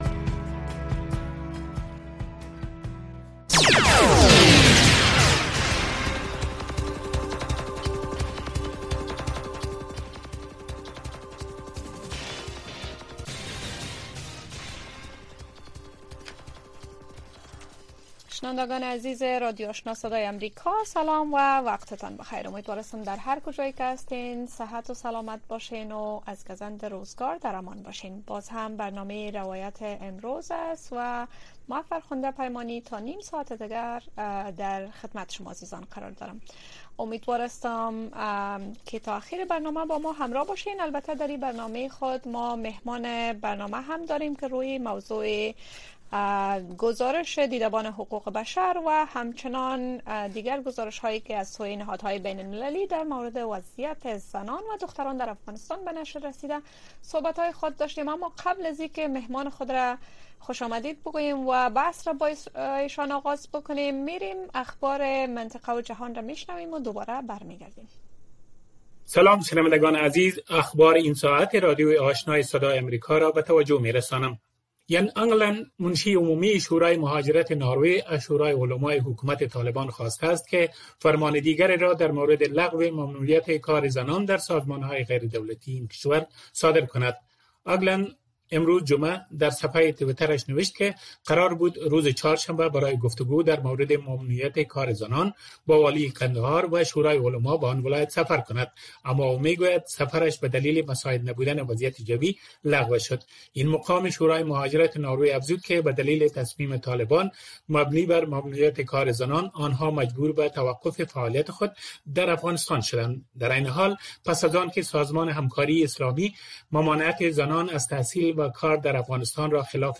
در برنامۀ روایت امروز شرح وضعیت در افغانستان را از زبان شهروندان و شرکت کنندگان این برنامه می‌شنوید. این برنامه هر شب از ساعت ٩:۳۰ تا ۱۰:۰۰ شب به گونۀ زنده صدای شما را پخش می‌کند.